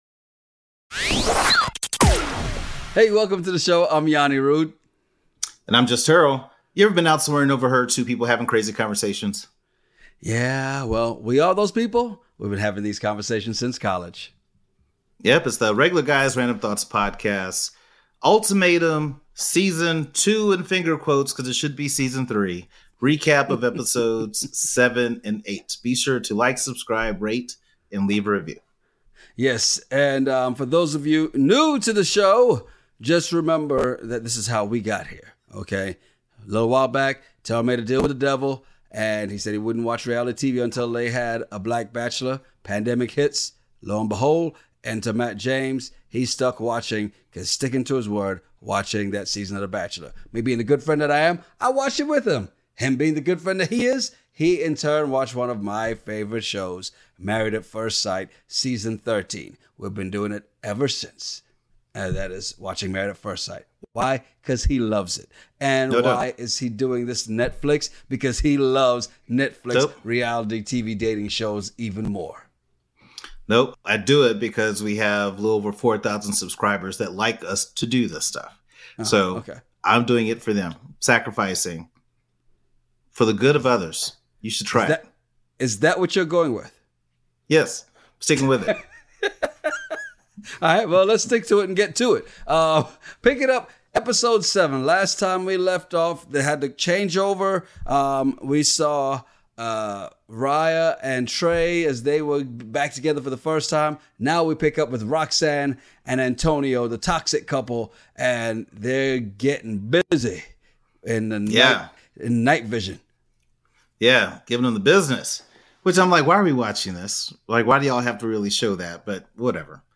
Ever been somewhere and overheard two guys having a crazy conversation over random topics? Well we are those guys and we have been having these conversations since college.